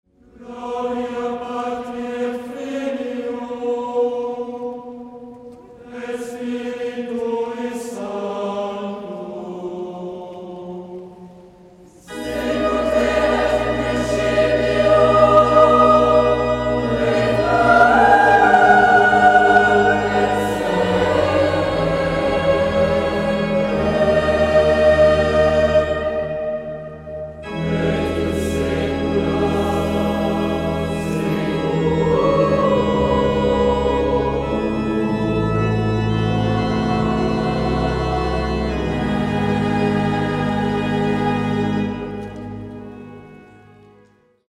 Confitebor-faux-bourdon-E.mp3